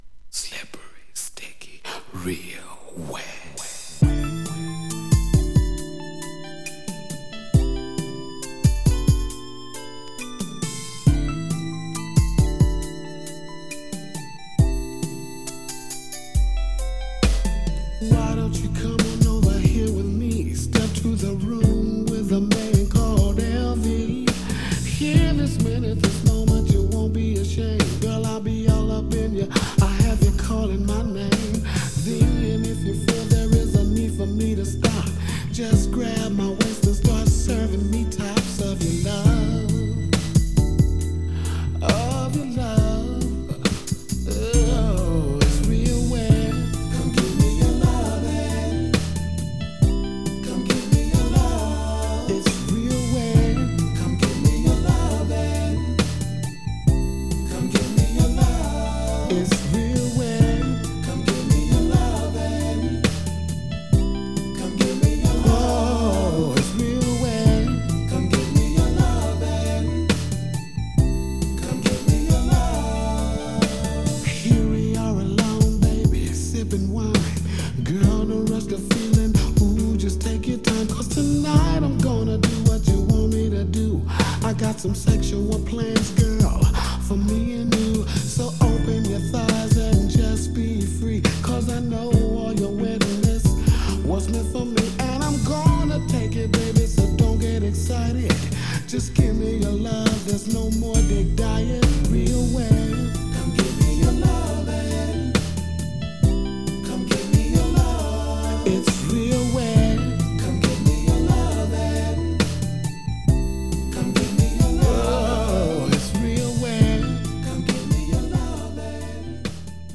スウィート/R&B